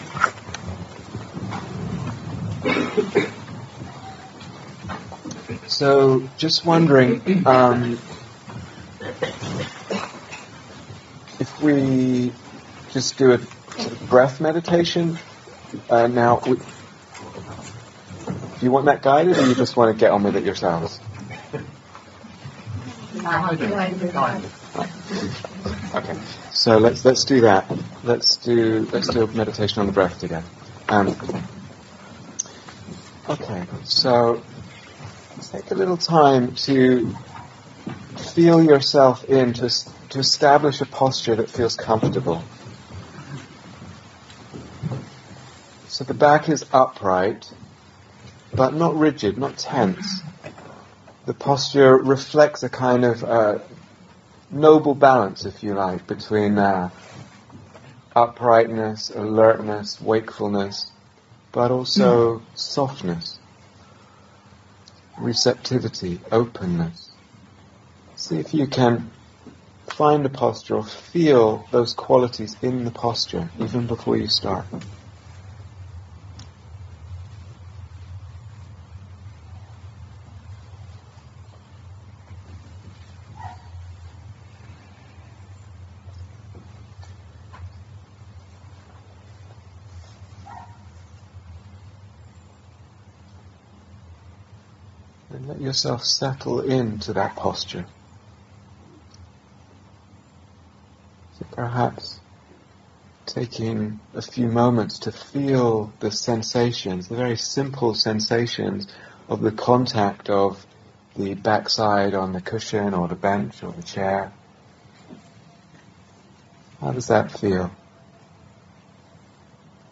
Guided Meditation
Day Retreat, London Insight 2013